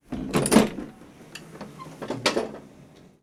cerradura
chasquido
golpe
manilla
Sonidos: Hogar